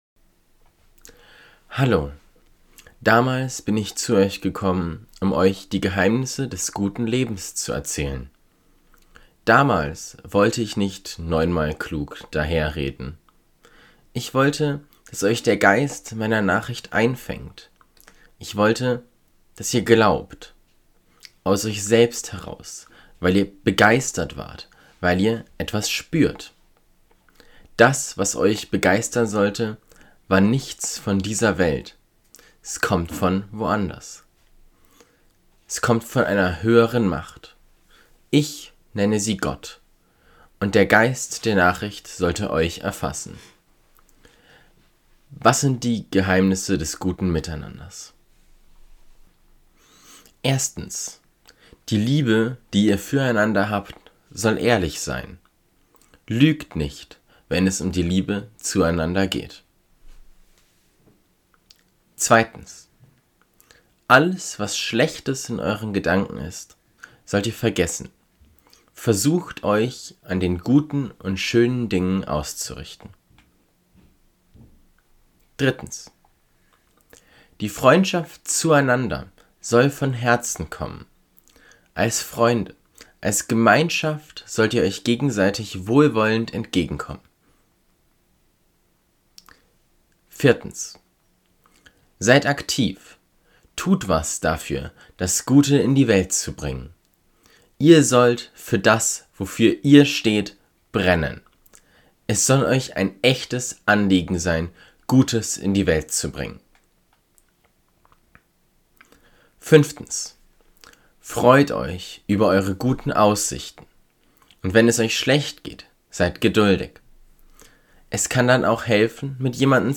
liest den Brief des Paulus (frei nach 1. Korinther 2, 1-10 und Römer 12, 6-9)